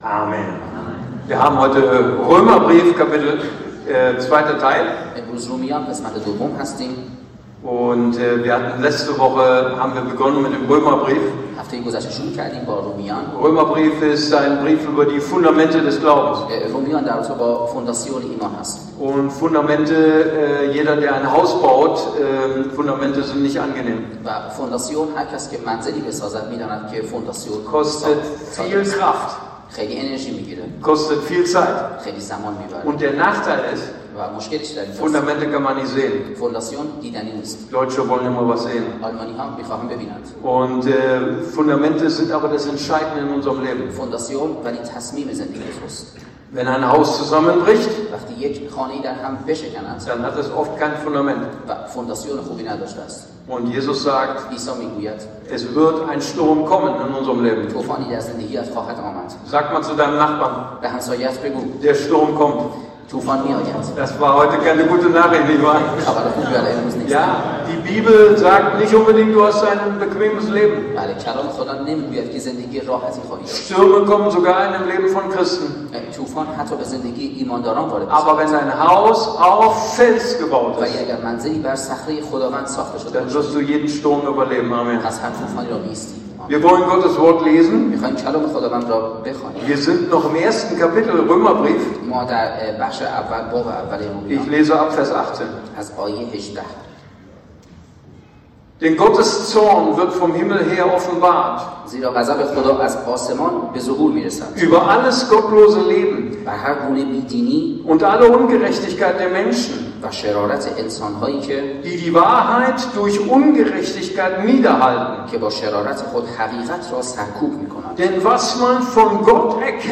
April 2025 45 Minuten ICB Predigtreihe Römerbrief Das „5.